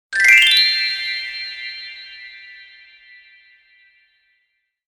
Magical Fantasy Swoosh Sound Effect
Description: Magical fantasy swoosh sound effect.
Transition sound. Audio logo.
Genres: Sound Logo
Magical-fantasy-swoosh-sound-effect.mp3